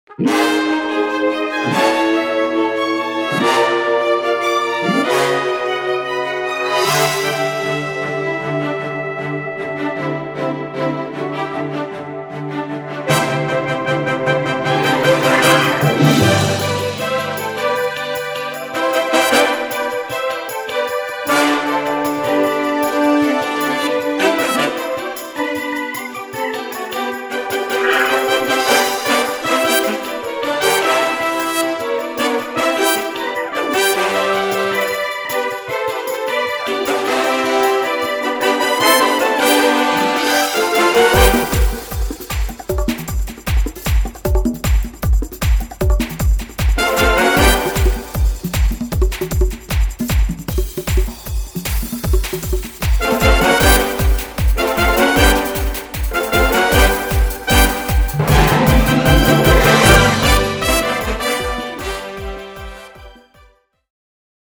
Click Track Layer